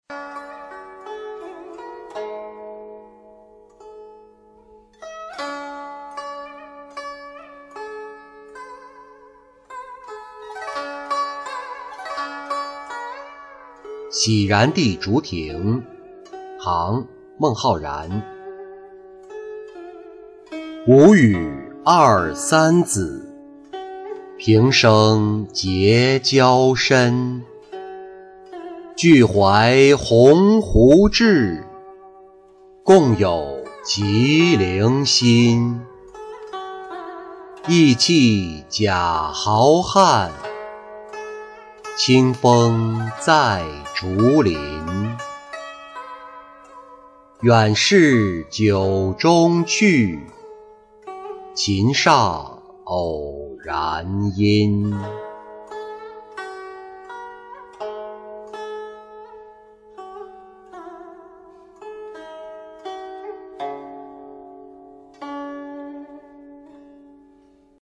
洗然弟竹亭-音频朗读